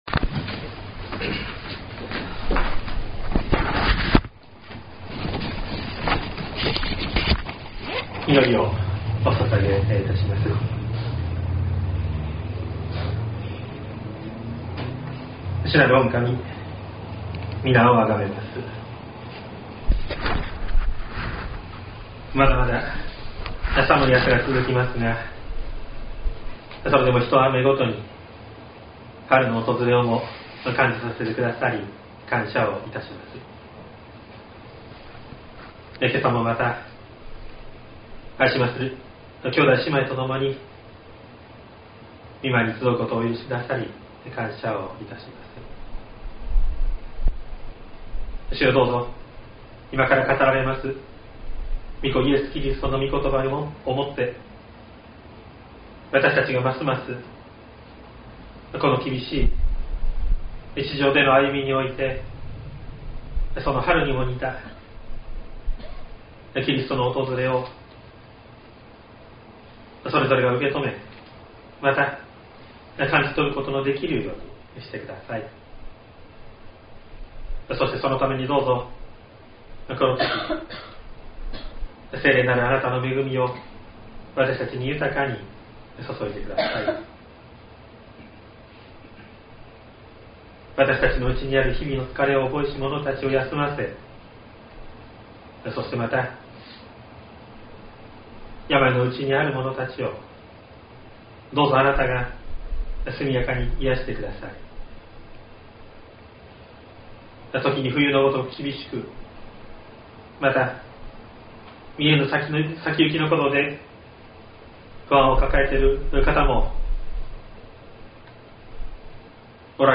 音声ファイル 礼拝説教を録音した音声ファイルを公開しています。